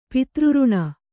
♪ pitř řṇa